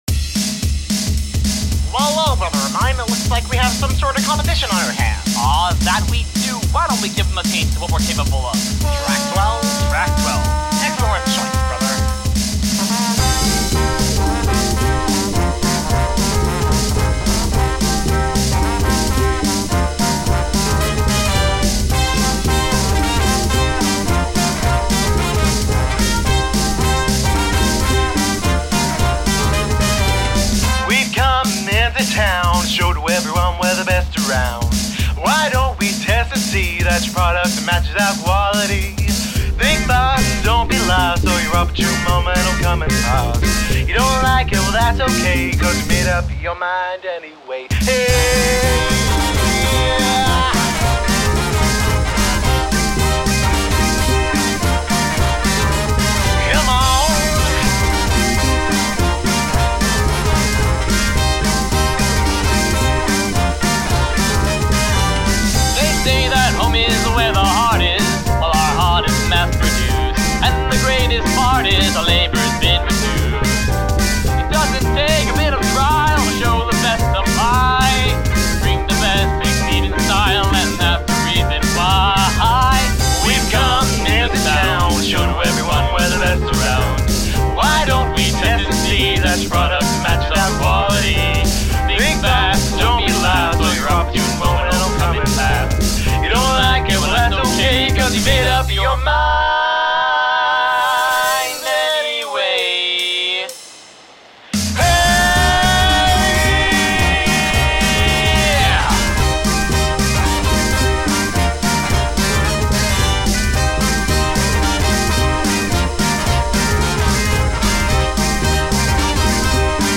Guitar
Trumpets, Trombones
Bass
Drums
Piano